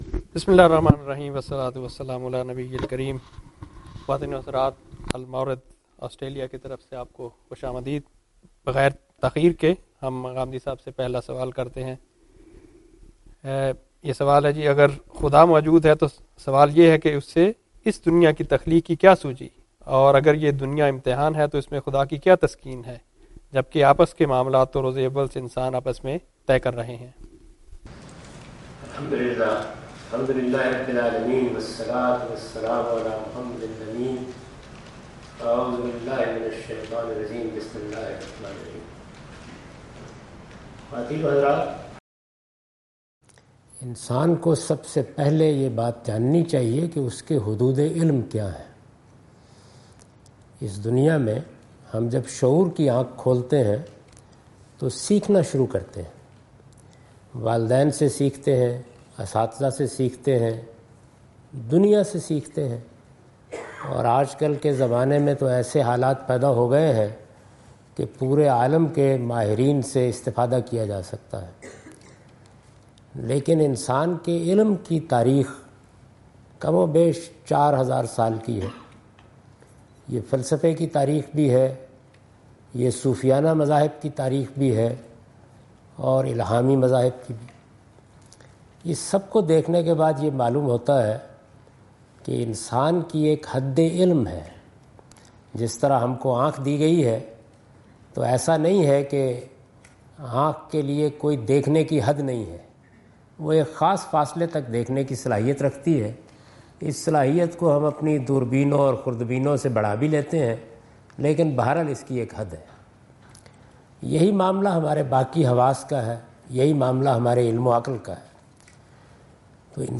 Javed Ahmad Ghamidi answering the questions of audience in Macquarie Theatre, Macquarie University, Sydney Australia on 04th October 2015.